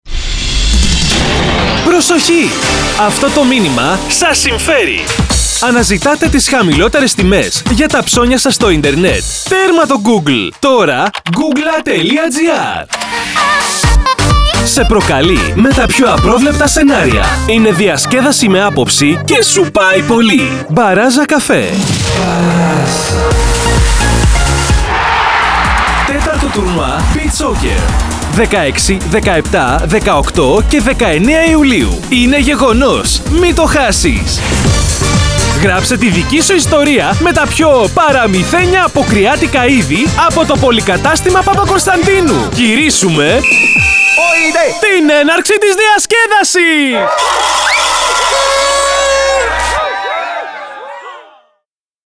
Sprechprobe: Werbung (Muttersprache):
Dynamic Greek male voiceover artist, with over 10 years of experience. Can perform theatrical and cartoon roles, sing and do rap.